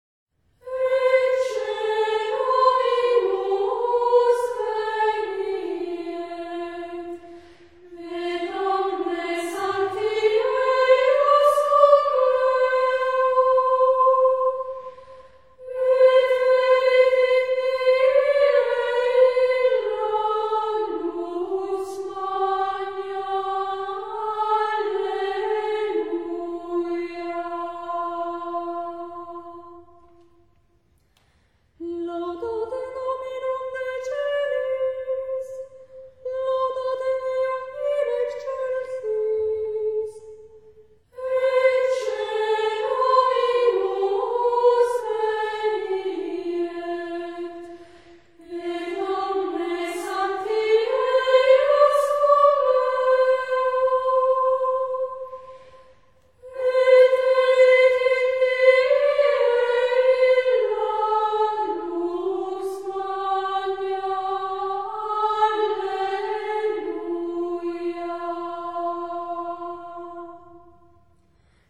In Dulci Jubilo – Salve Festa Dies
Хоралы годичного богослужебного круга в женском исполнении. Запись сделана 11 сентября 1992 г. в Вероне, Италия.